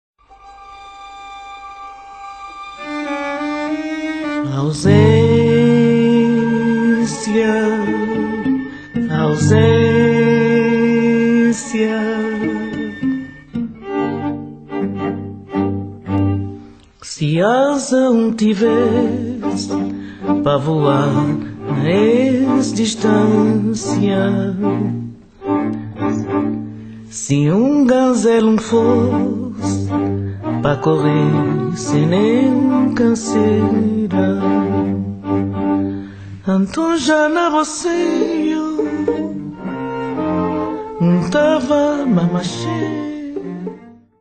Moderne Tangos/ Tango-Atmosphäre